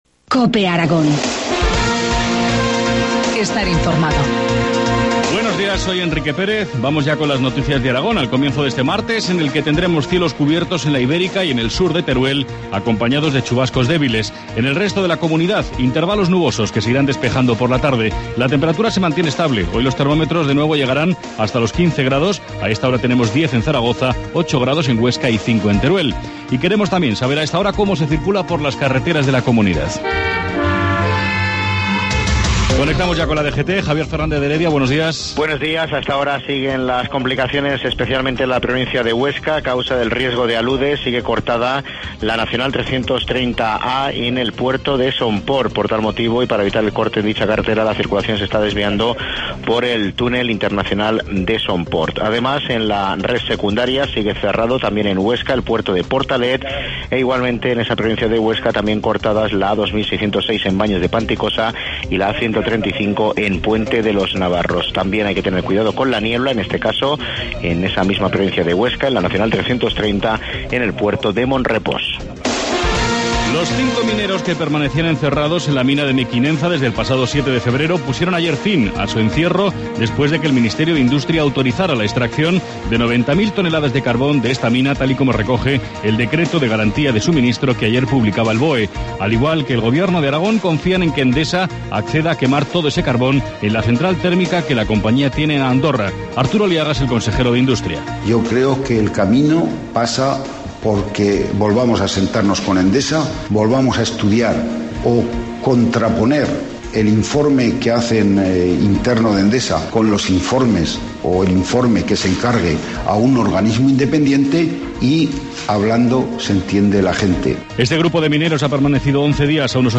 Informativo matinal, martes 19 de febrero, 7.25 horas